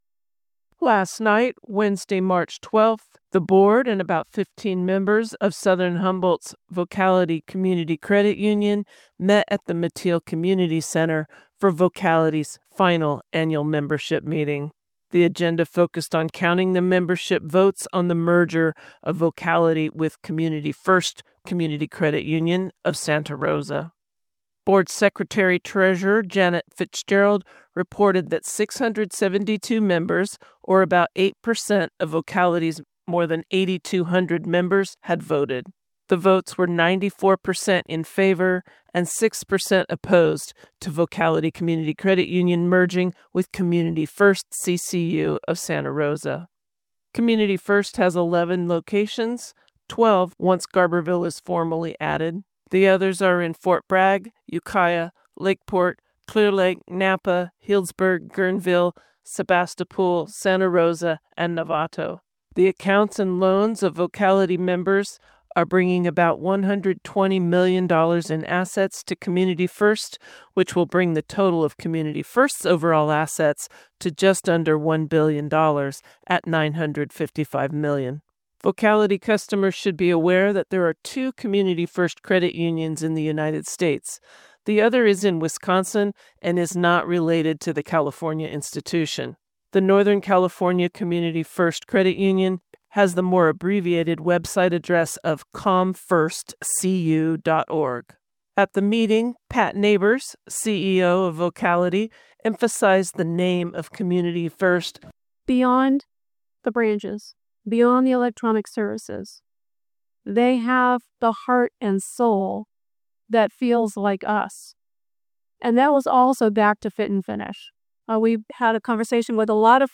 It's official, Southern Humboldt's locally controlled credit union will merge with the regional Community First Credit Union. KMUD News brings you this report on what you need to know for your banking business through this process.